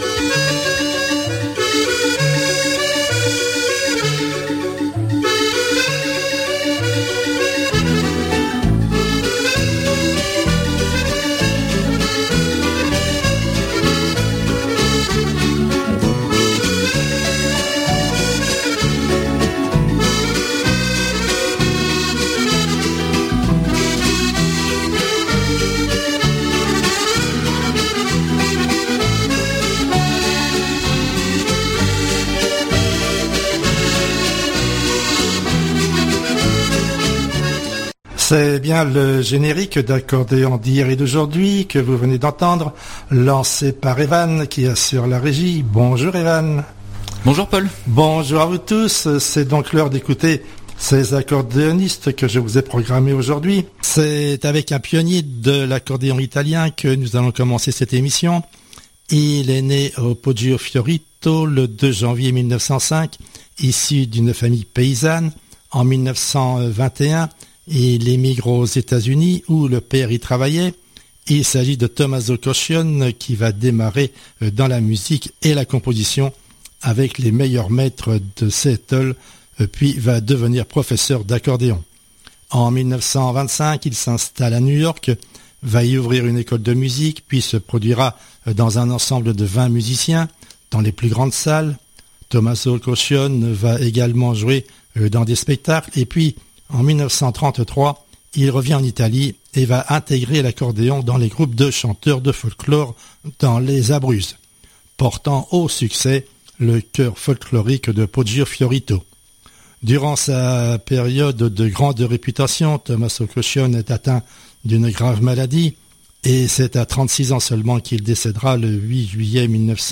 Accordéon